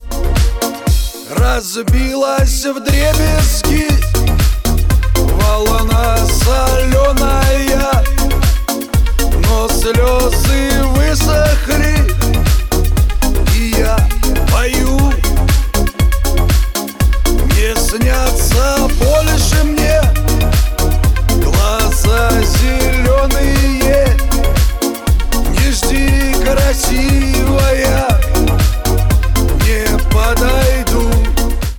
• Качество: 128, Stereo
грустные
русский шансон